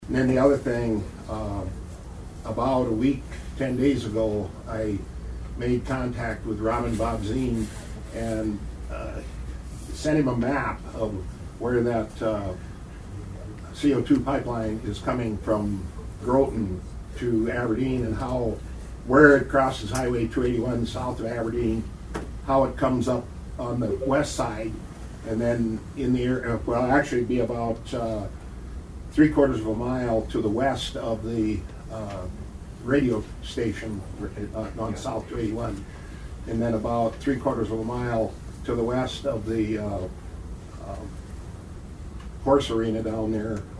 ABERDEEN, S.D.(HubCityRadio)- Former Brown County Commissioner Dennis Feickert told the Brown County Commissioner about his converation with Aberdeen city officials during the meeting Tuesday about the CO2 pipeline.